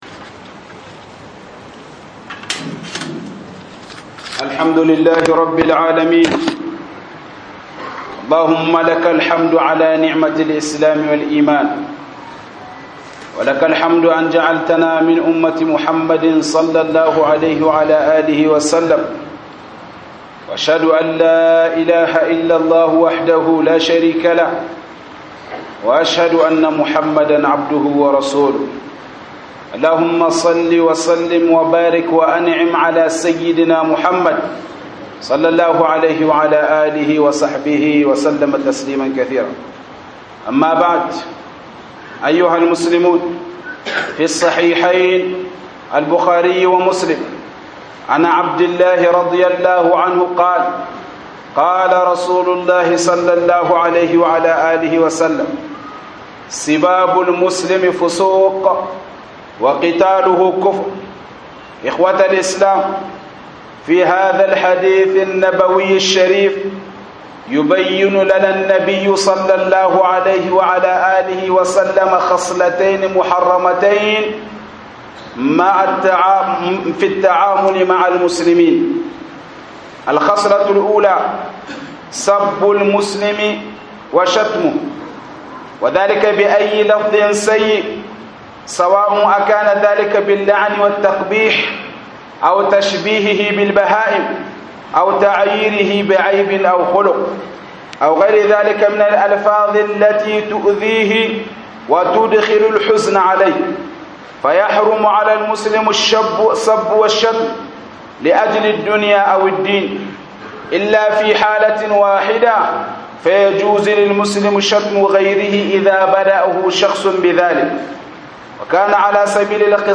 ibn hzm hdb - HUDUBA